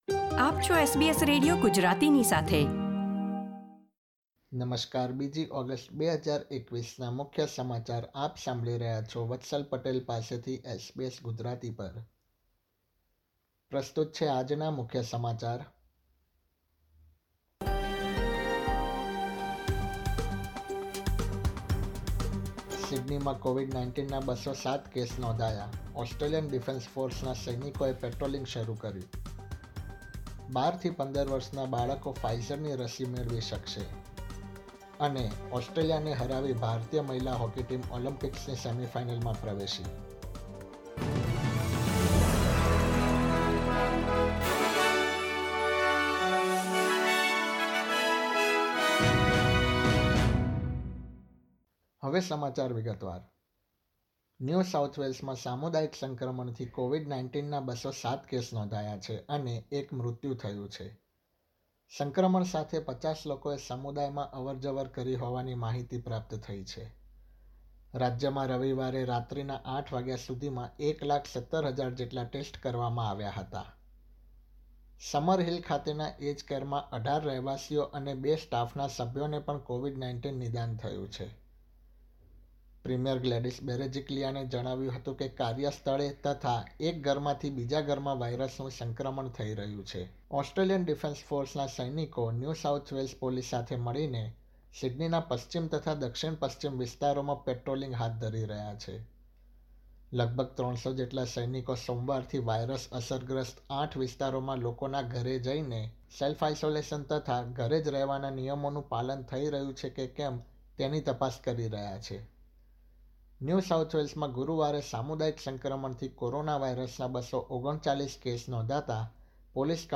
SBS Gujarati News Bulletin 2 August 2021
gujarati_0208_newsbulletin.mp3